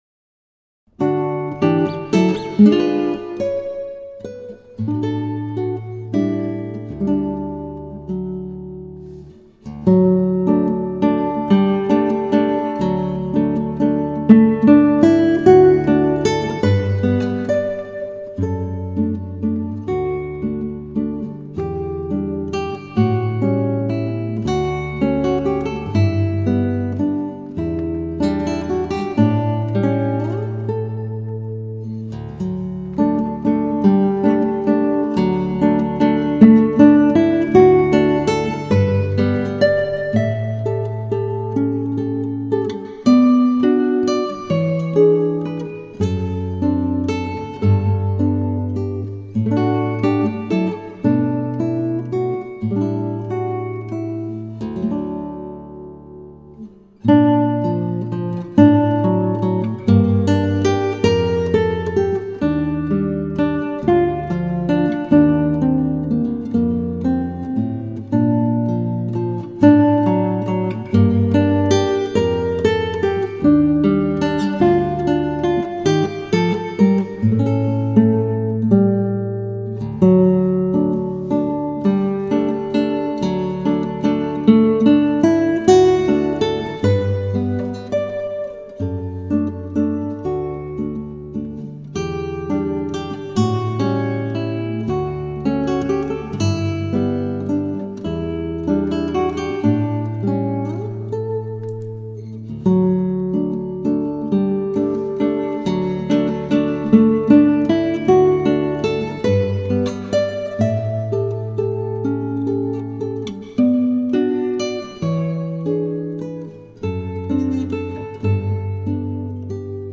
16 stukken voor gitaar.
• Gitaar solo